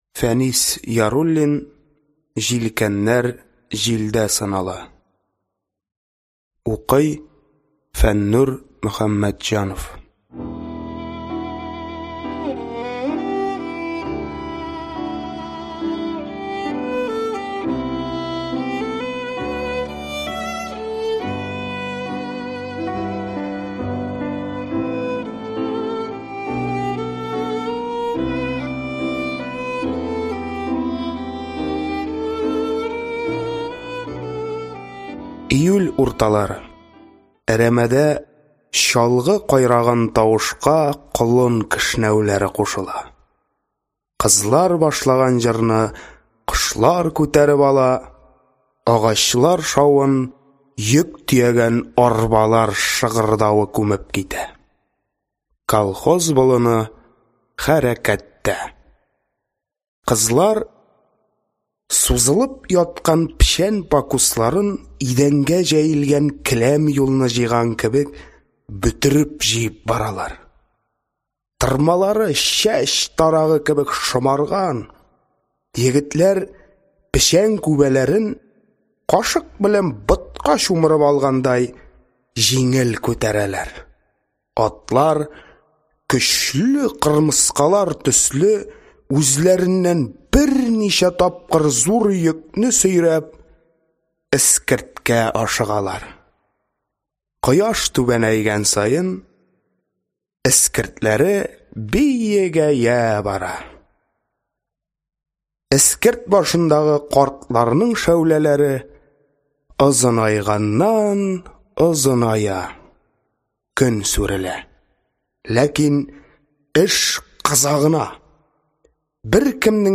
Аудиокнига Җилкәннәр җилдә сынала | Библиотека аудиокниг